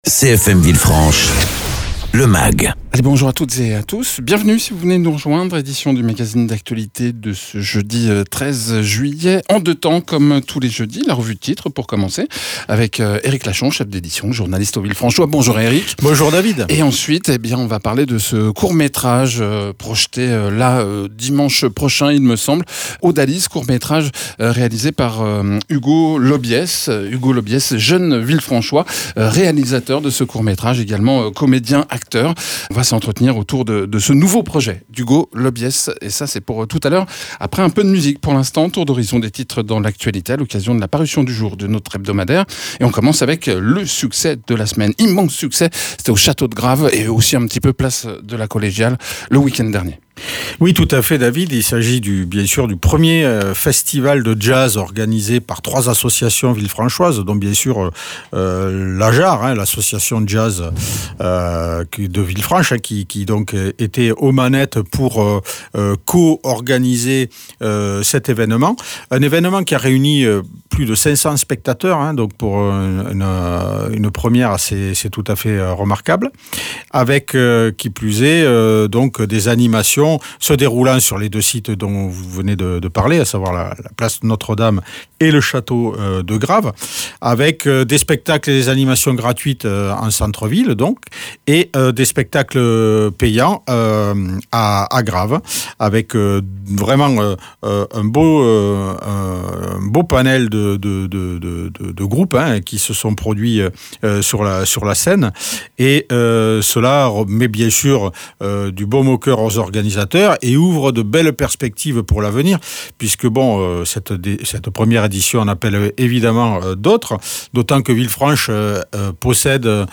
réalisateur et acteur